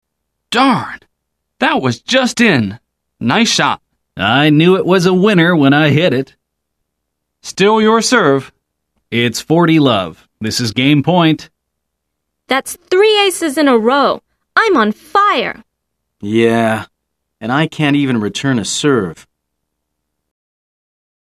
來聽老美怎麼說？